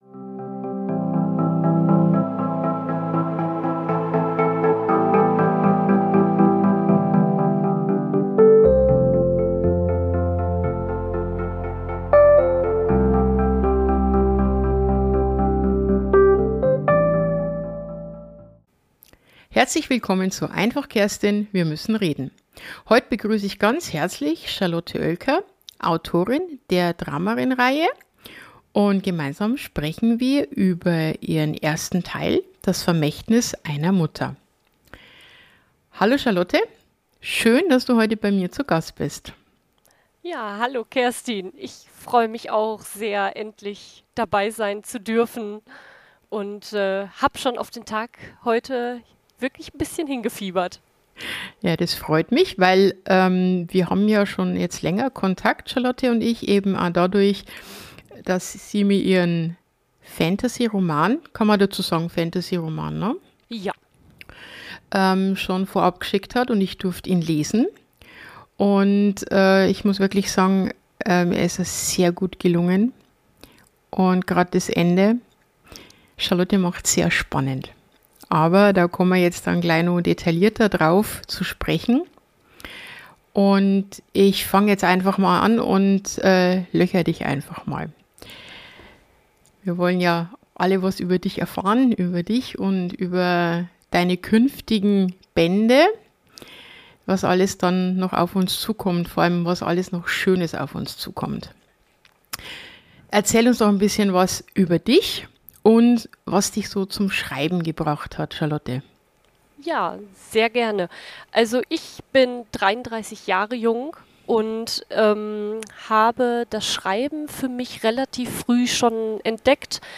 Buchvorstellung Dramárin